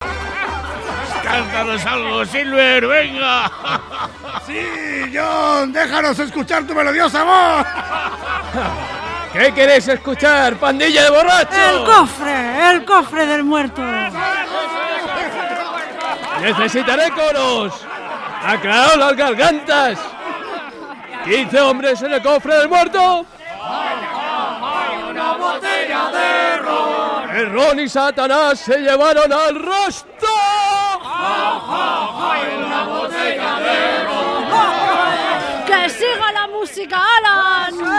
Un grupo de personas ciegas representó la ficción sonora de RNE ‘La isla del tesoro’ con voluntarios de RTVE
La grabación se llevó a cabo en el Estudio Música 2 de la Casa de la Radio, en Prado del Rey (Madrid).
La Plataforma de Voluntariado de RTVE y nuestra Organización se han unido para que este grupo de personas puedan participar en una experiencia creativa en la que, con la ayuda de sonidos, efectos especiales y la imaginación, se transporten al siglo XVIII, en un mundo de aventuras y piratas que tanta inspiración ha proporcionado al cine, la televisión, la literatura, los cómics y los videojuegos.
Las personas ciegas, que se prepararon los textos en braille, contaron con el apoyo y la orientación de voluntarios de RNE,